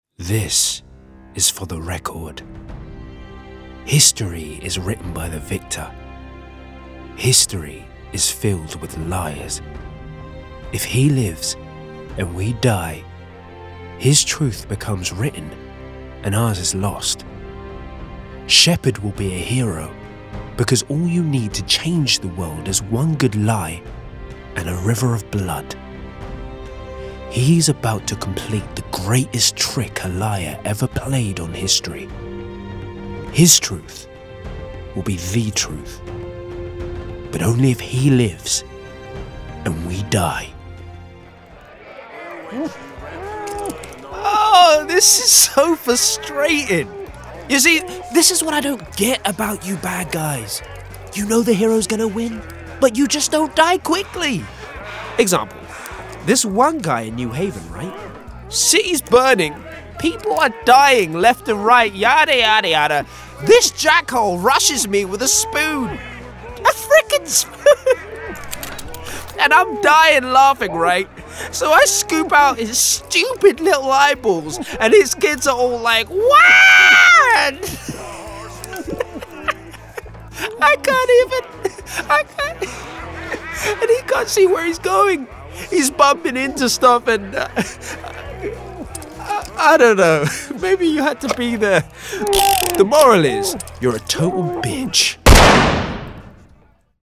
Video Game Reel
• Native Accent: London
His distinctive tone undoubtedly cuts through. A compelling choice for voice work that needs presence and personality.